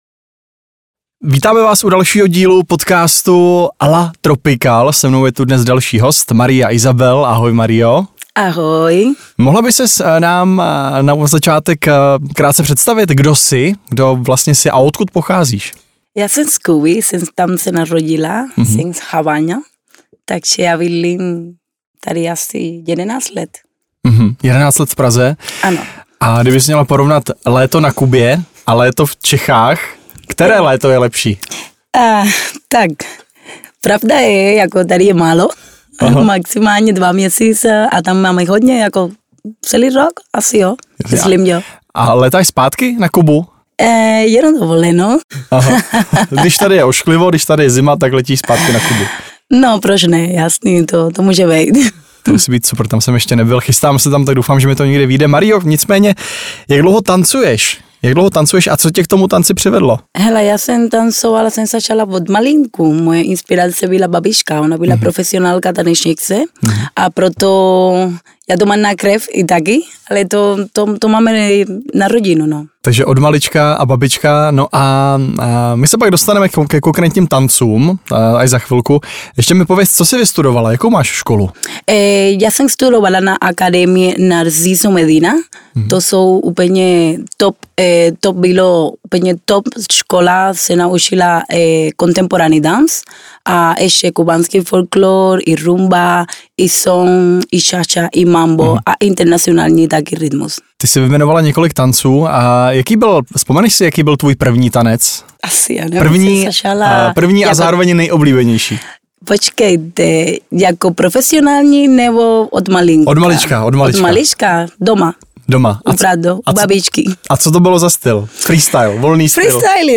Tropical podcast  Rozhovor